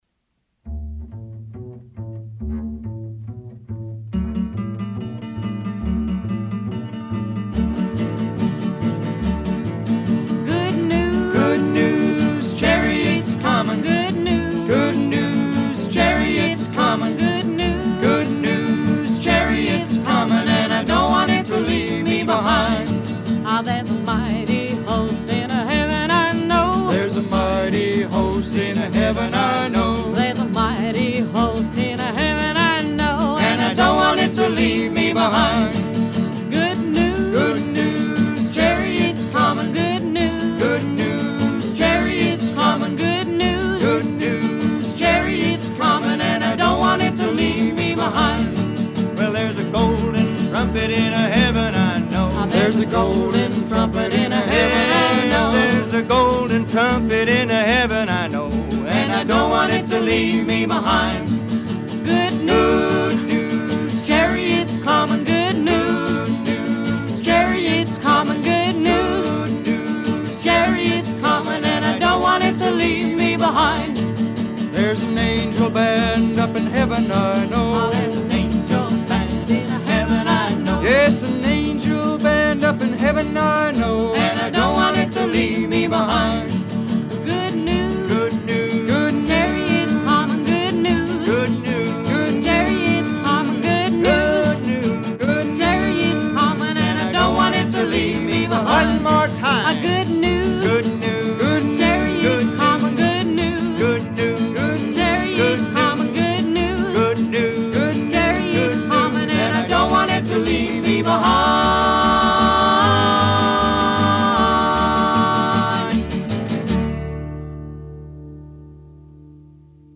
GOSPEL
(prob.) The Enigmas: